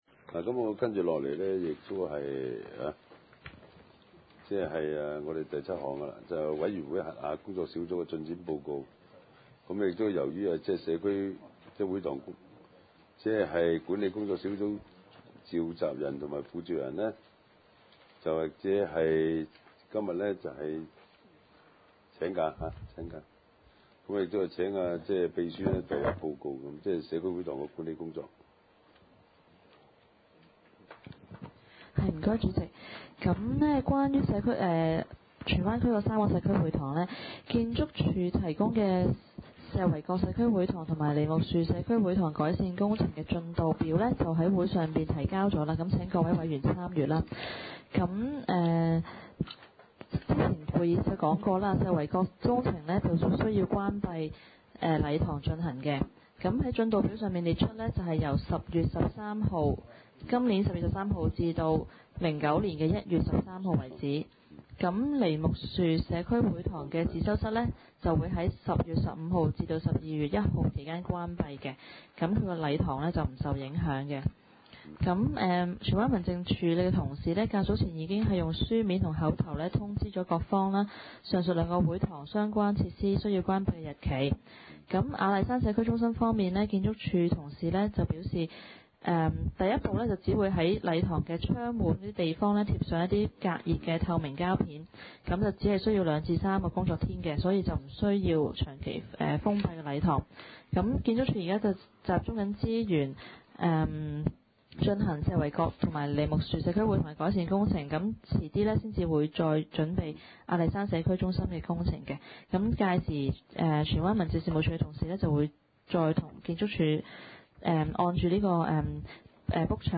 地區設施管理委員會第五次會議
荃灣民政事務處會議廳